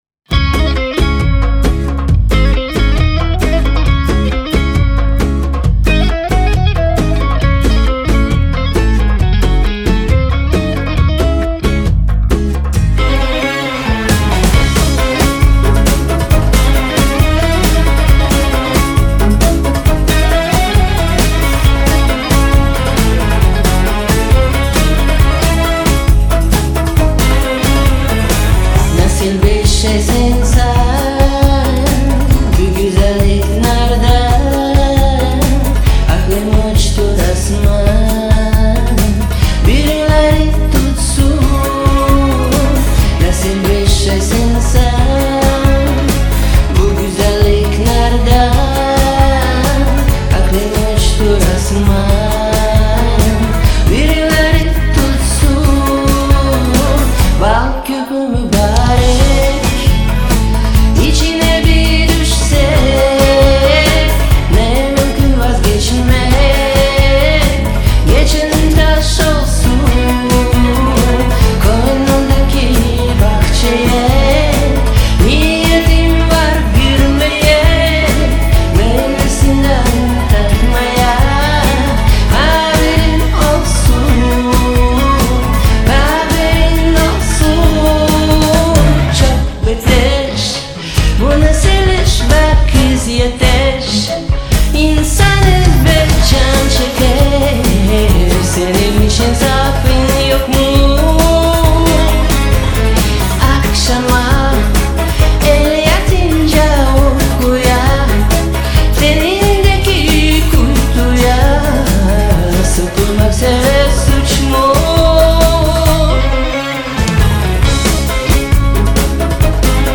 тюрецка песнь